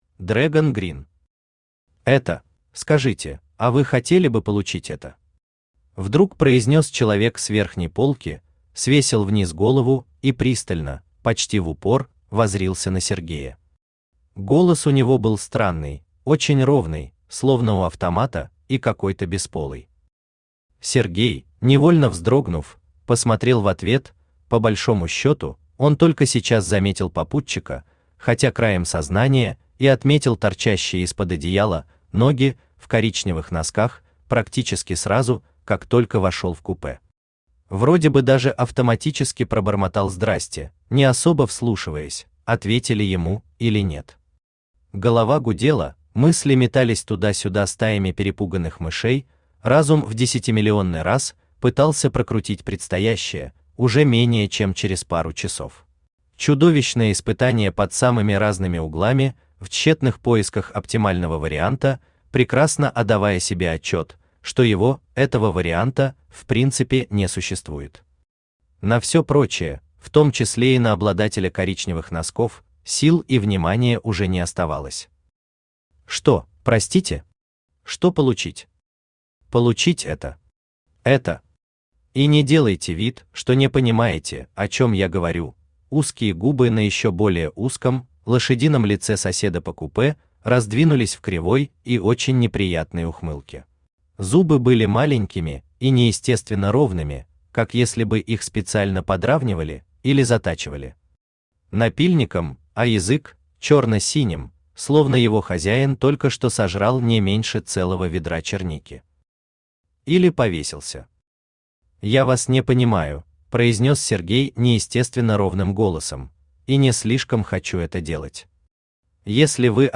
Аудиокнига Это | Библиотека аудиокниг
Aудиокнига Это Автор Dragon Green Читает аудиокнигу Авточтец ЛитРес.